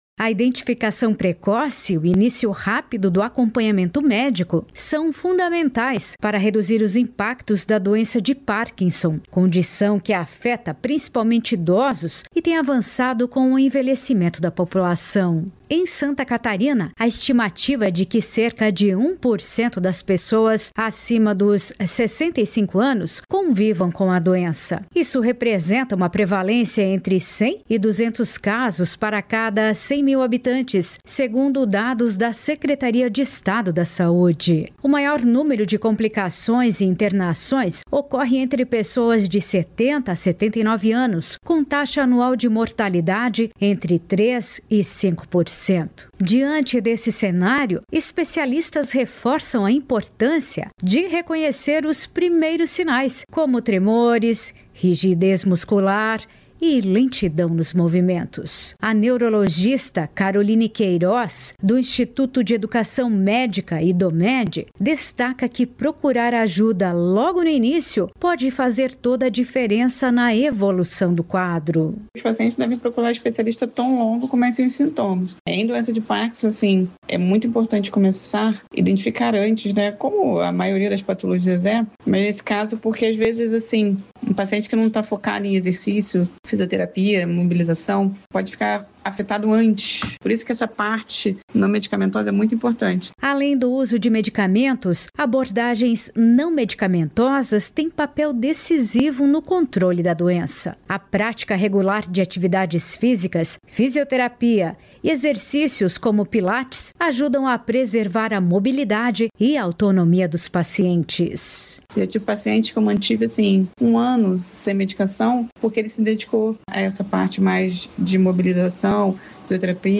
Entrevistado: